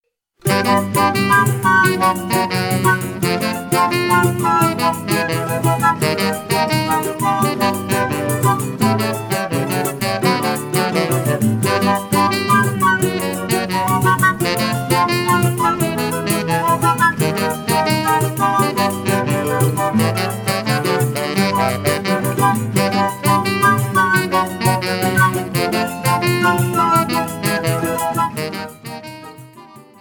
tenor saxophone
flute